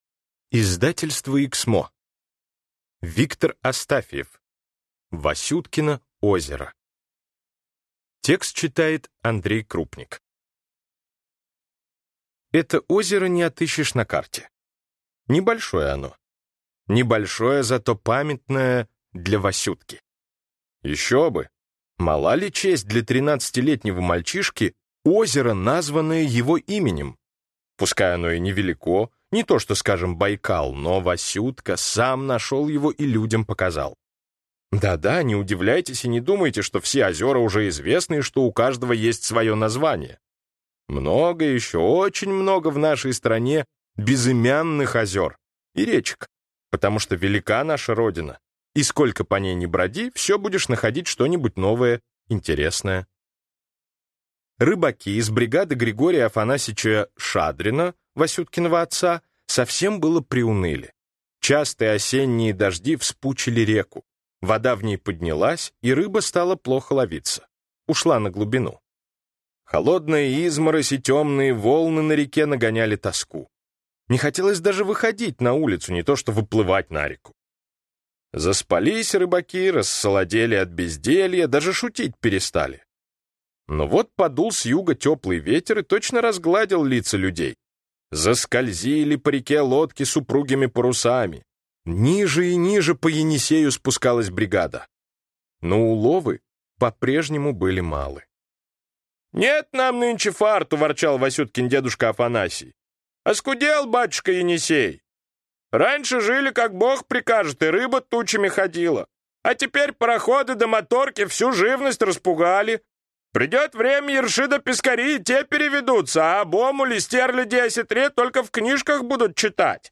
Аудиокнига Васюткино озеро | Библиотека аудиокниг